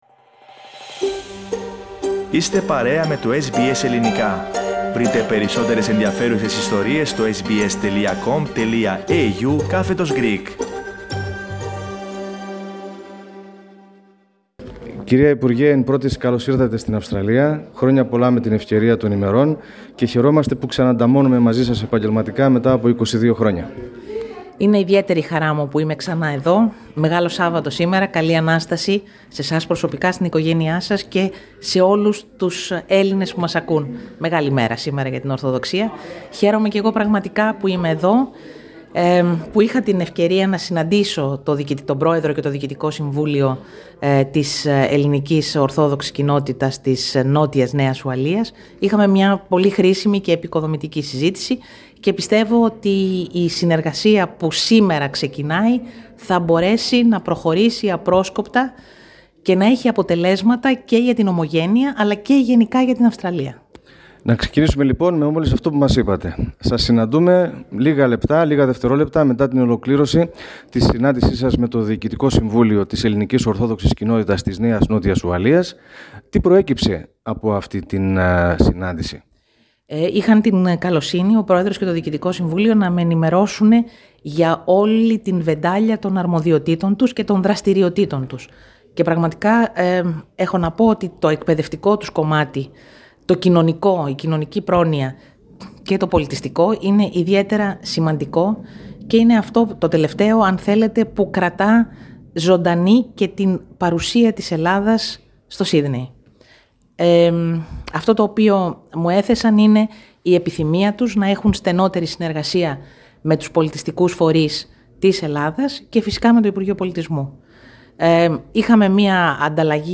Μετά το πέρας αυτής της συνάντησης η κυρία Μενδώνη παραχώρησε συνέντευξη στο Ελληνικό Πρόγραμμα της Ραδιοφωνίας SBS (SBS Greek) την οποία μπορείτε να ακούσετε πατώντας play στην κεντρική εικόνα.